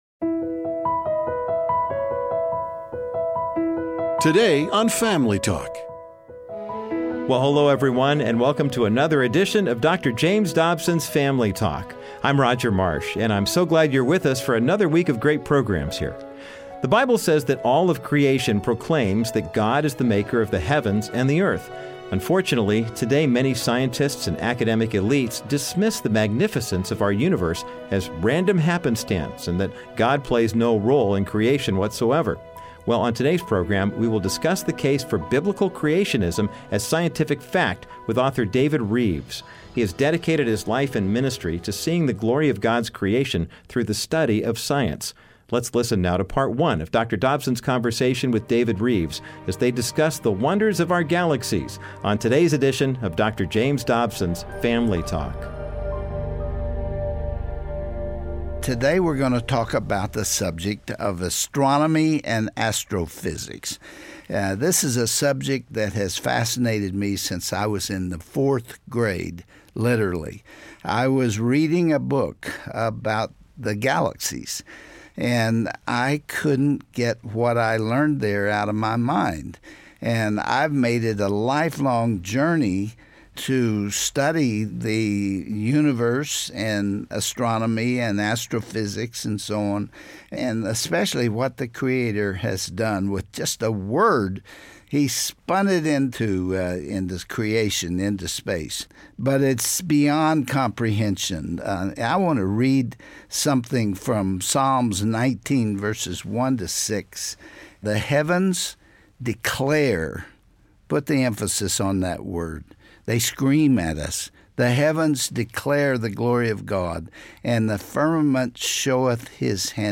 Dont miss this illuminating talk, today on Dr. James Dobsons Family Talk.